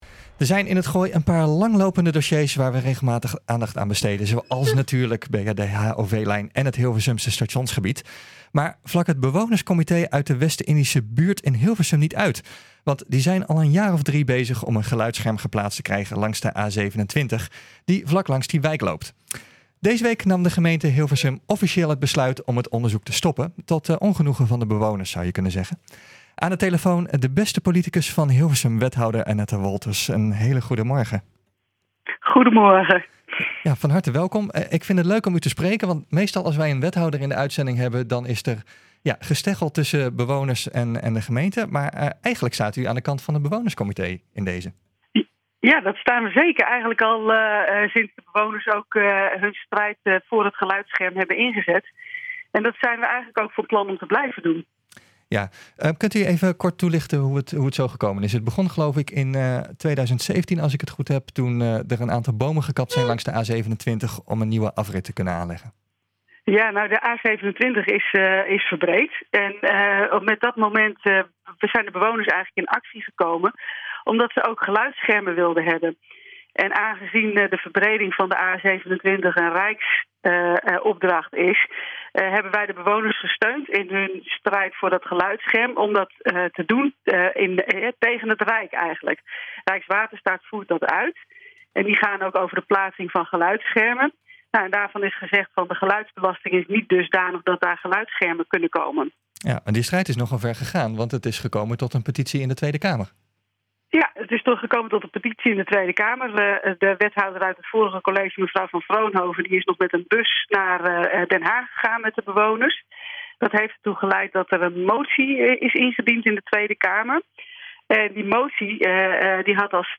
Deze week nam de gemeente Hilversum officieel het besluit om het onderzoek te stoppen, tot ongenoegen van de bewoners. Aan de telefoon wethouder Annette Wolthers.